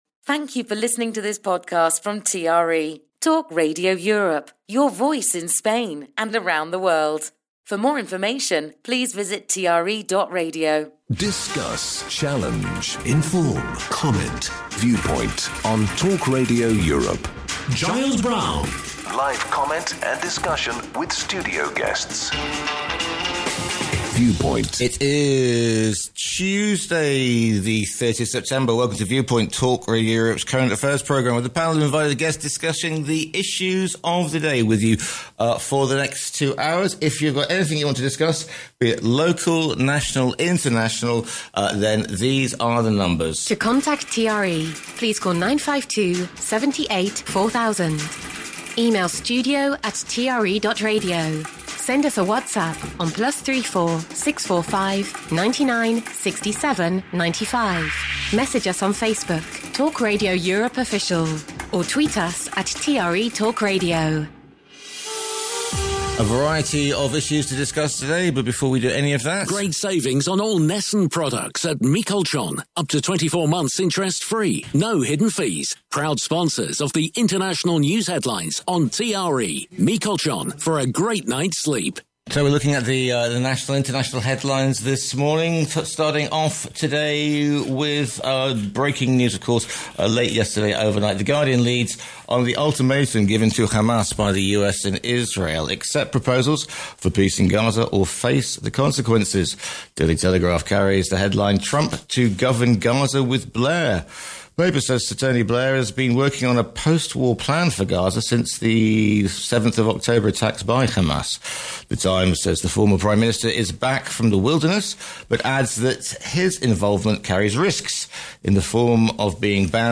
his panel of guests